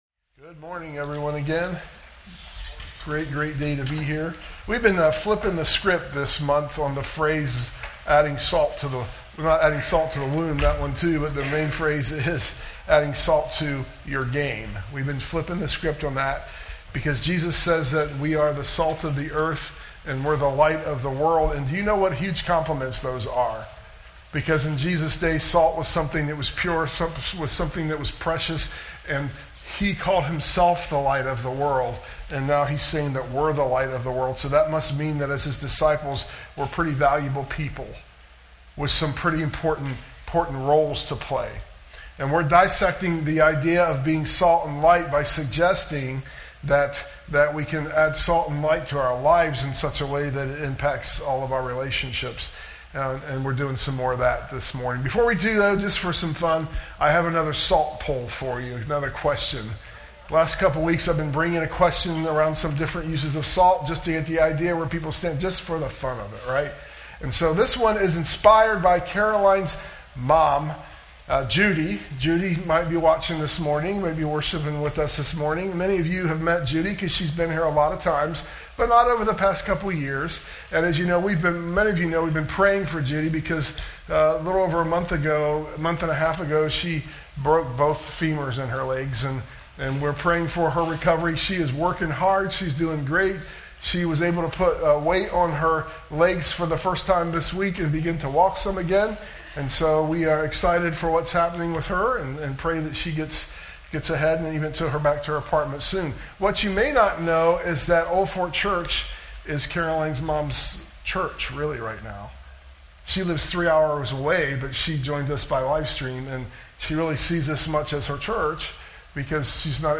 Good news... the livestream worked this week all the way to the end.